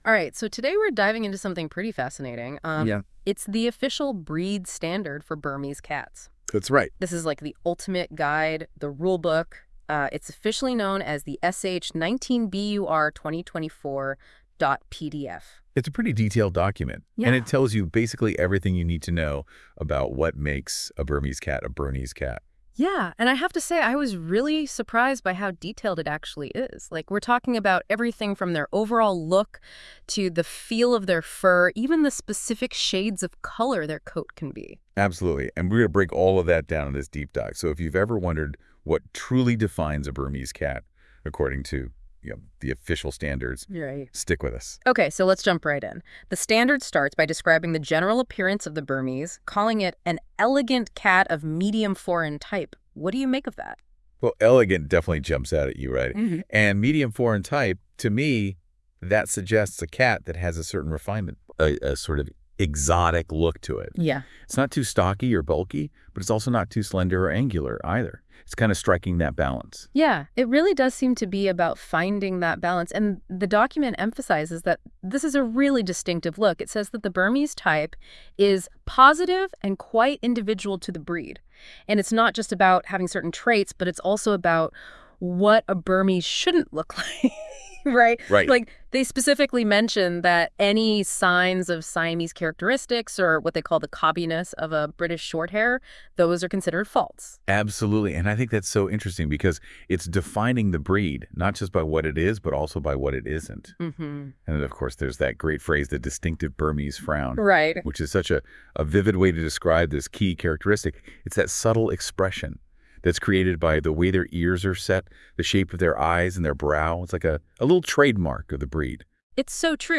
We’ve created an audio version that discusses the content in a more conversational format.
🎧 This podcast version has been generated using AI voice technology, based on the content of the official PDF from New Zealand Cat Fancy